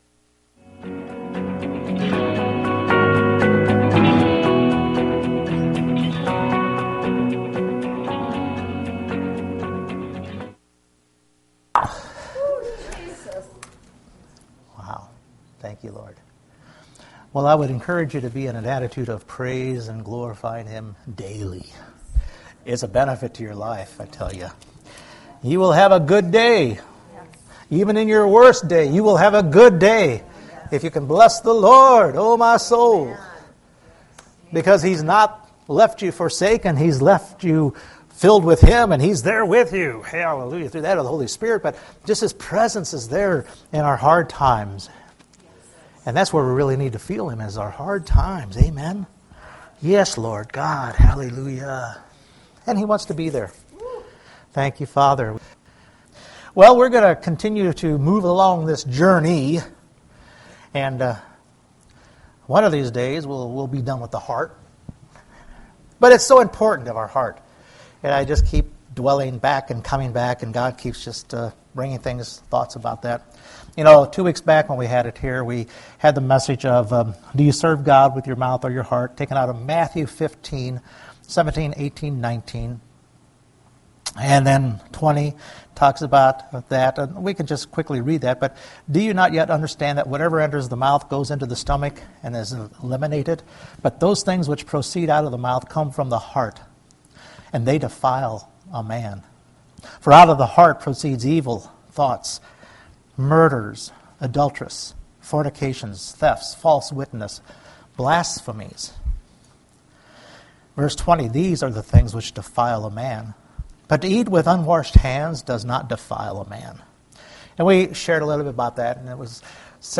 Several Service Type: Sunday Morning We continue looking at our heart